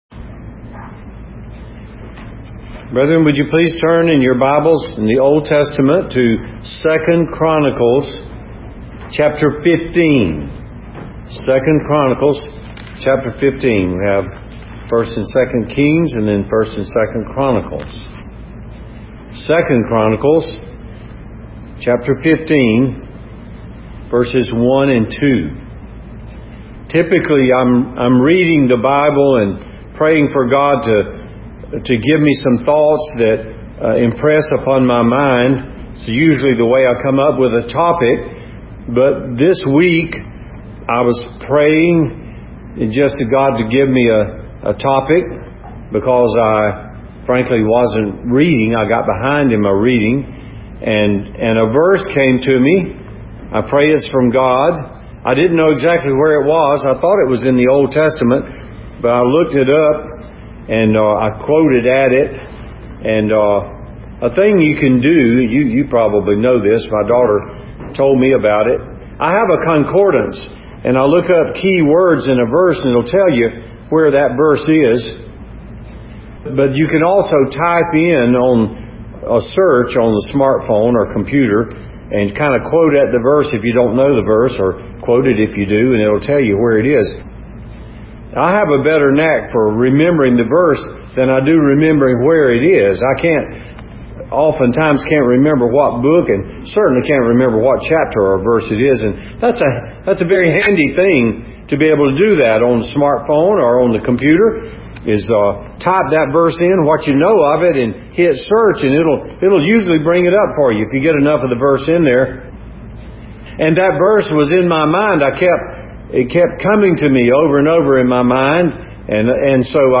Sermon by Speaker